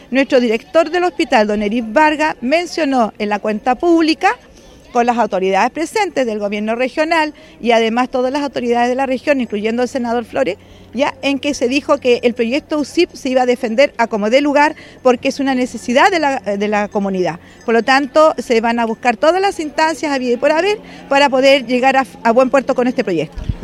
Testimonios de la protesta